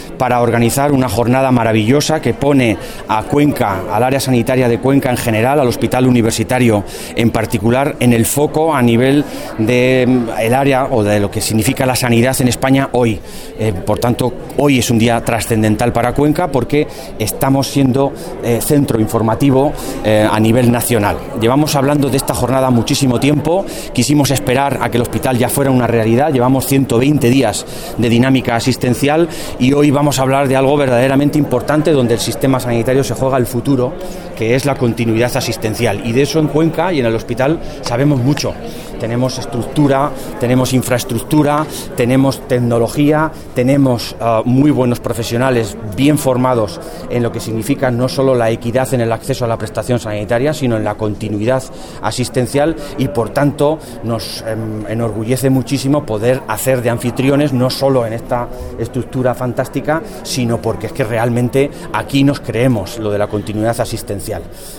Celebrada la Jornada «El abordaje de la continuidad asistencial en CLM» en el Hospital Universitario de Cuenca: avances para un modelo asistencial centrado en las necesidades del paciente
Intervención